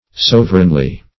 Search Result for " sovereignly" : The Collaborative International Dictionary of English v.0.48: Sovereignly \Sov"er*eign*ly\, adv.
sovereignly.mp3